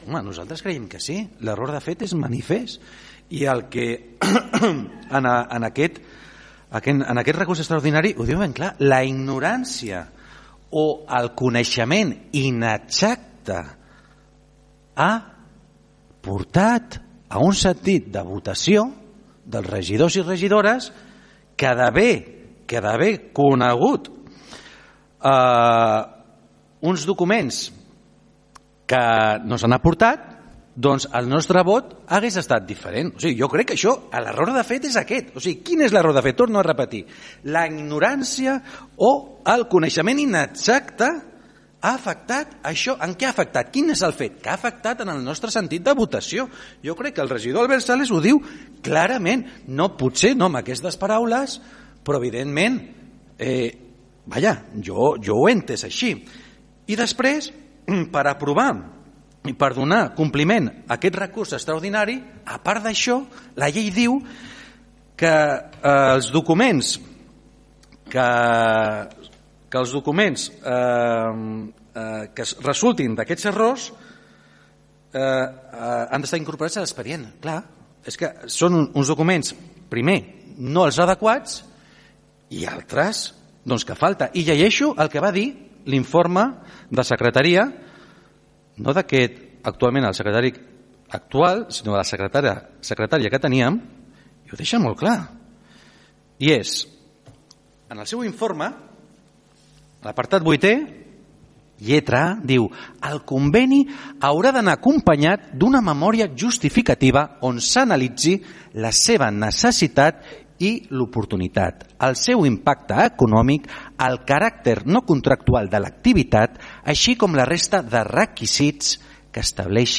El portaveu del PSC, Miquel Santiago, ha dit que per a ells sí que hi havia error de fet, perquè la ignorància o el coneixement inexacte de l’assumpte va afectar en el sentit de la votació: